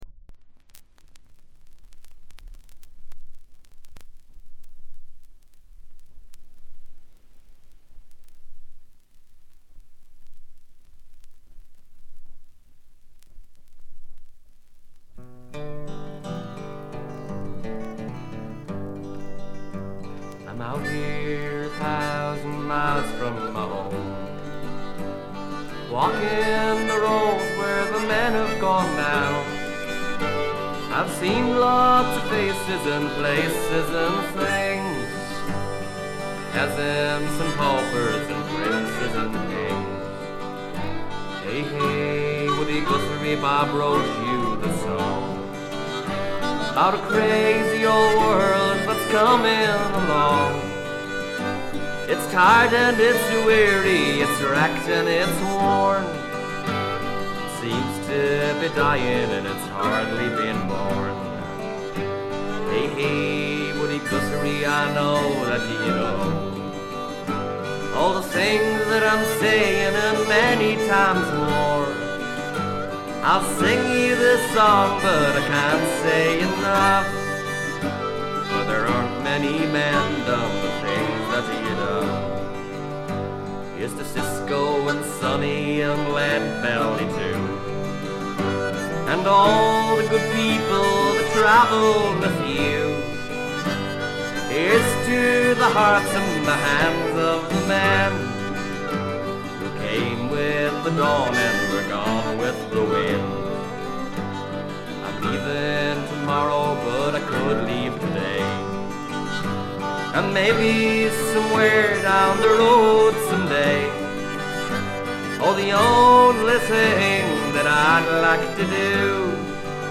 ほとんどノイズ感無し。
アイリッシュ・フォーク基本中の基本です。
中身は哀切なヴォイスが切々と迫る名盤。
試聴曲は現品からの取り込み音源です。